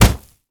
punch_grit_wet_impact_05.wav